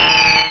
pokeemerald / sound / direct_sound_samples / cries / altaria.aif